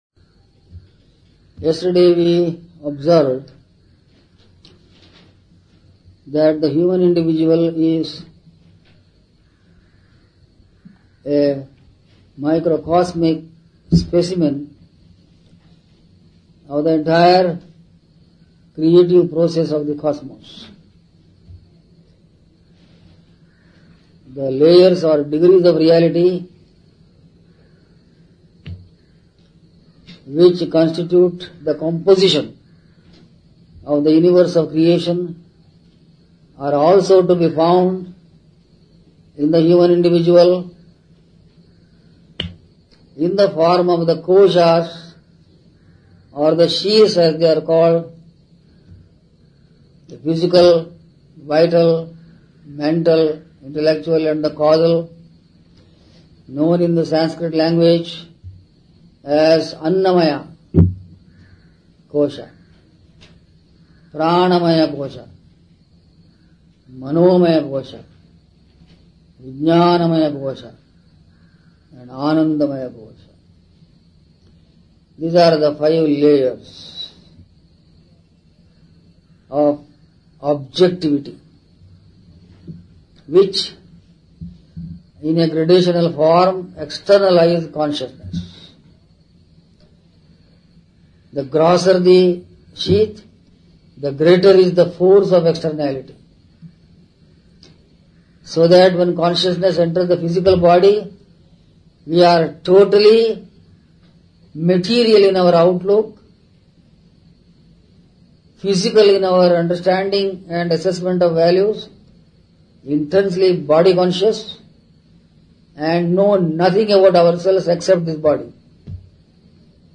Upanishads – A series of talks on the Upanishads.